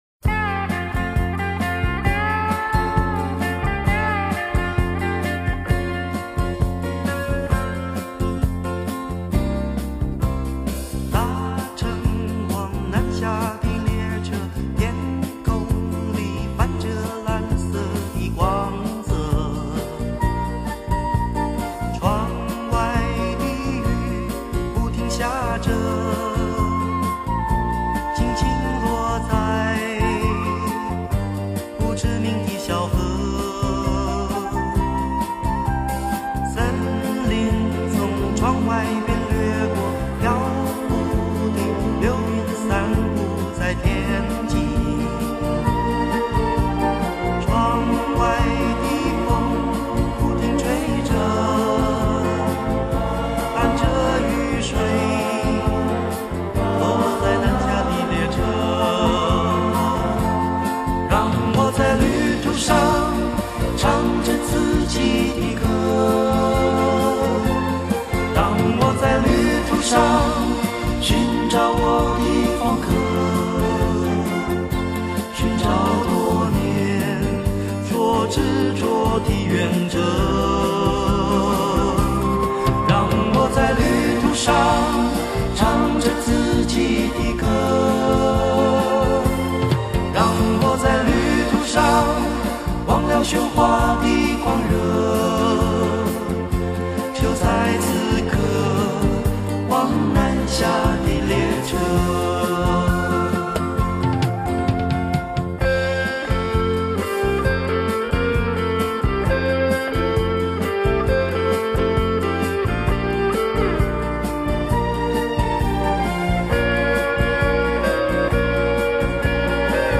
地　　区：台湾
八张黄金版CD之100首曲目，全以最新24位元数位录音技术处理，重现当年歌手原声重唱，音色更为甘亮饱满。